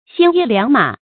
鮮衣良馬 注音： ㄒㄧㄢ ㄧ ㄌㄧㄤˊ ㄇㄚˇ 讀音讀法： 意思解釋： 見「鮮衣怒馬」。